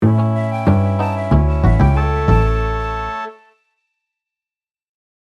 Art Type: Music